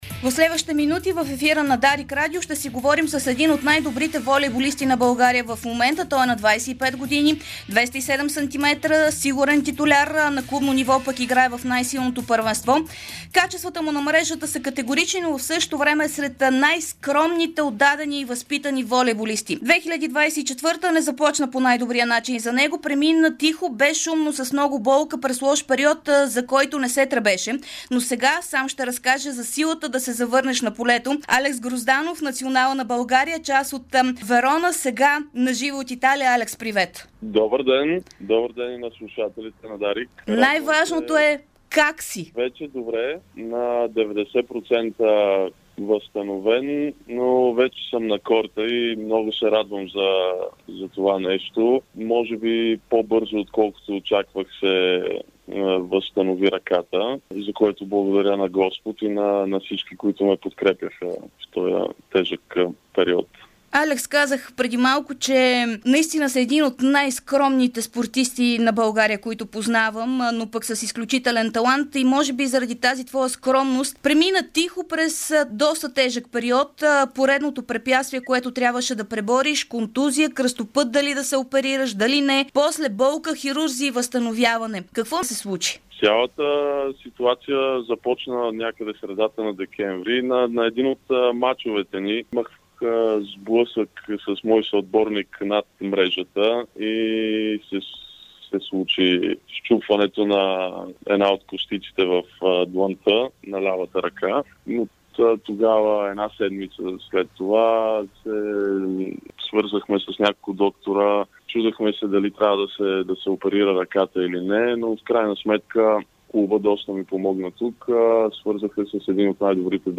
Волейболистът на италианския Верона и българския национал Алекс Грозданов даде ексклузивно интервю в ефира на Дарик радио, в което разказа за пръв път... (07.02.2024 13:04:13)